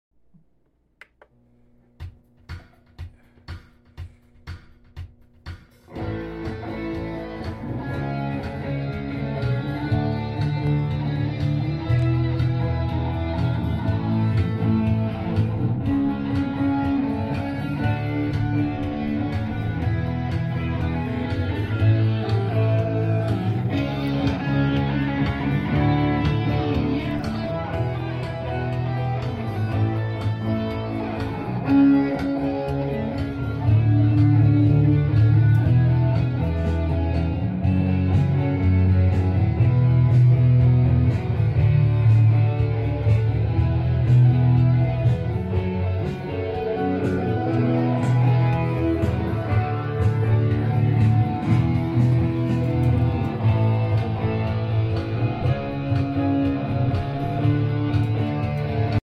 Gibson guitars &:a Fender amp sound effects free download
a Fender amp Mp3 Sound Effect Gibson guitars &:a Fender amp were used in this demo recording on a Zoom R20.